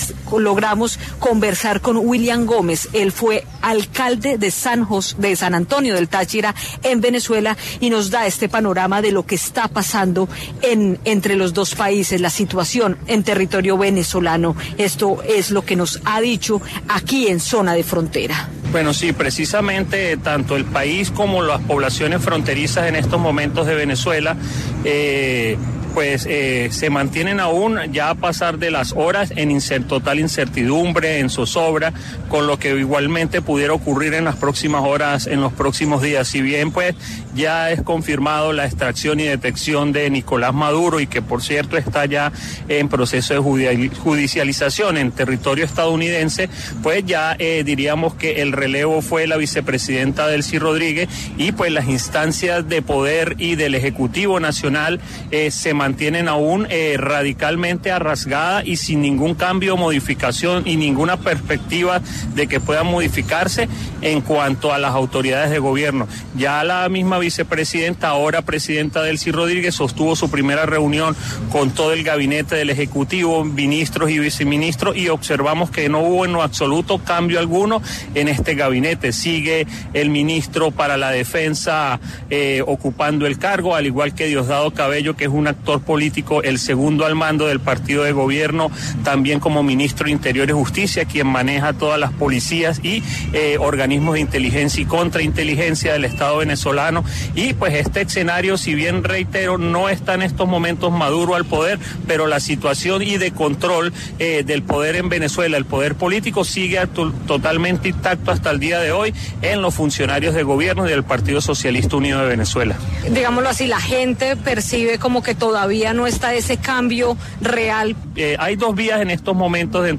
En 6AM de Caracol Radio estuvo el exalcalde de San Antonio de Táchira , Venezuela, William Gómez, quien habló sobre el panorama que se está viviendo en la zona fronteriza con Colombia